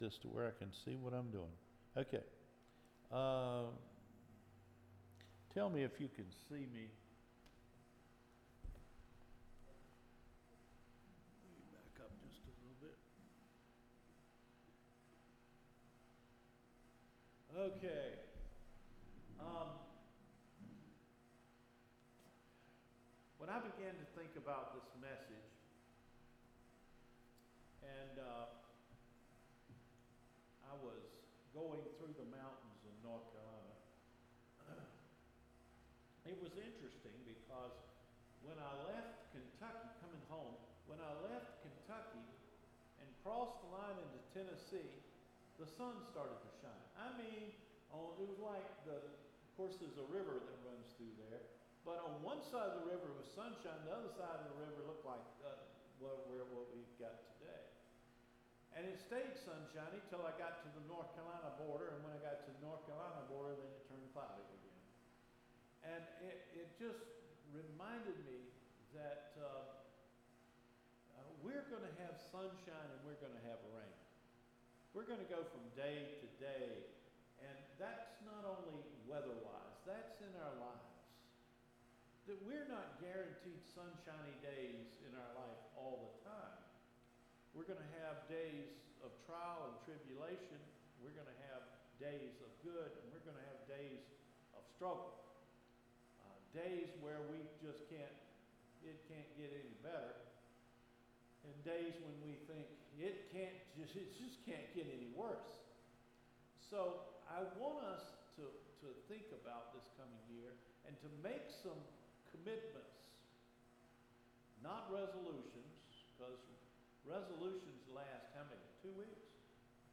JANUARY 3 SERMON – DON’T GO THERE